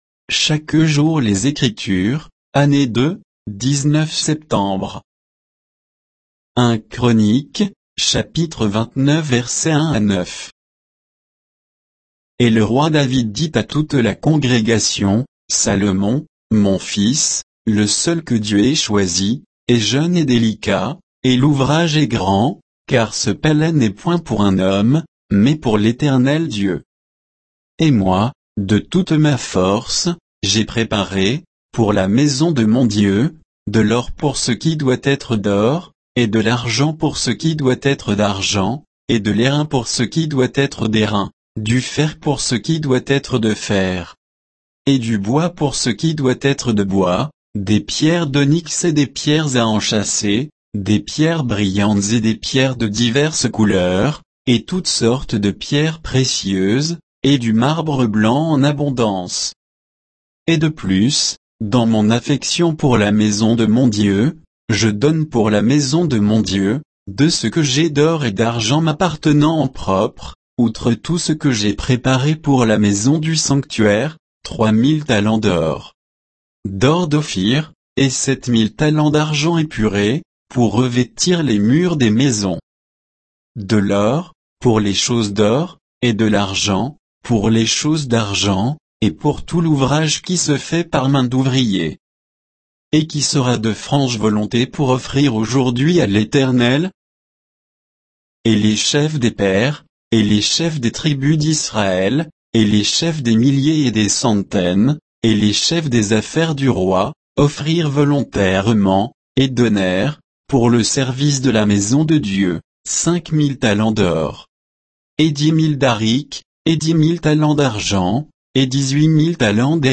Méditation quoditienne de Chaque jour les Écritures sur 1 Chroniques 29, 1 à 9